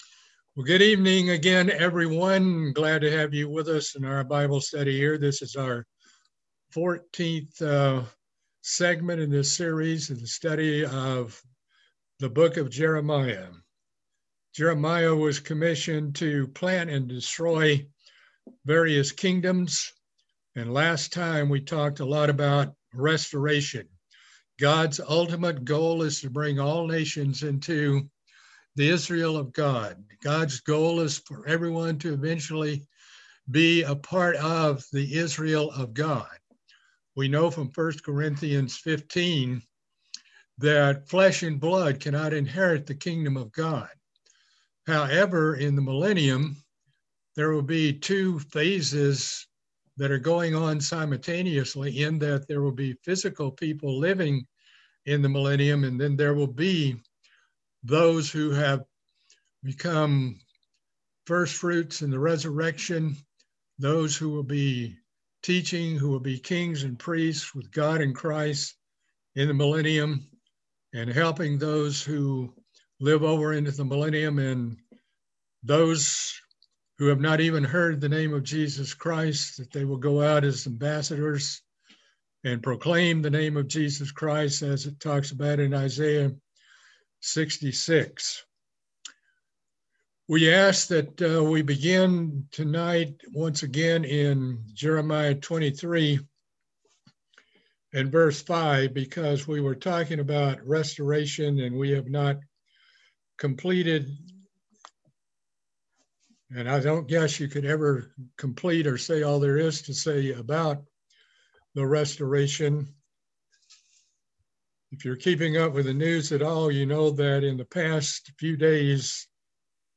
Bible study series on the book of Jeremiah - Part 14